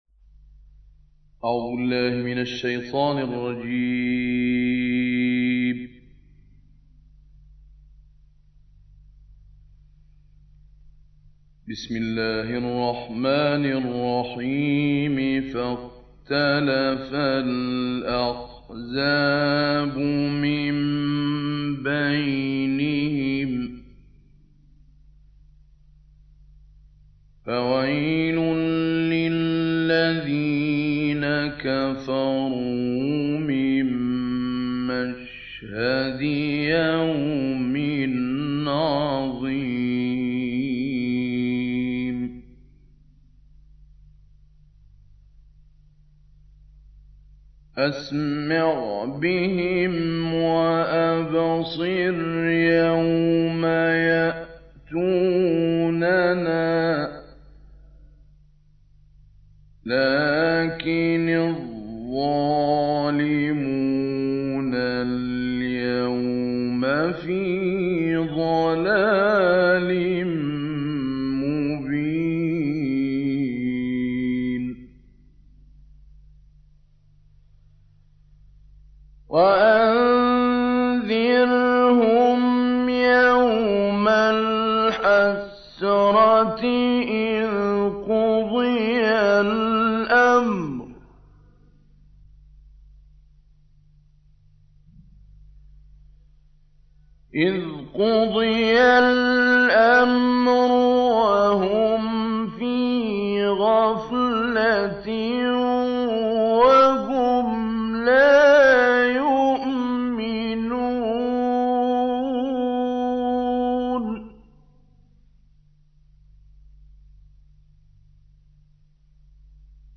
تلاوتی کمتر شنیده شده از محمود علی‌البناء/ آیاتی از سوره مریم+صوت
به گزارش خبرنگار قرآن و فعالیت‌های دینی خبرگزاری فارس؛ محمود علی البناء قاری نامدار جهان اسلام متولد ۱۹۲۶ و درگذشته ۱۹۸۵ کشور مصر است.